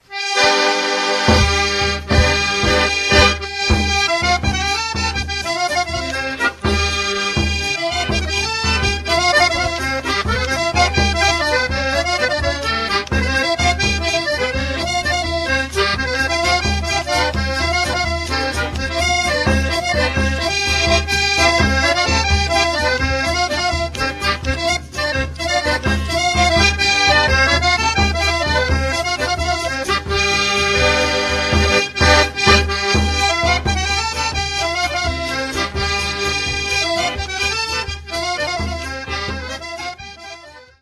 Oberek (okolice Gowarczowa, 1985)
harmonia przerobiona z akordeonu "Marinucci"
bębenek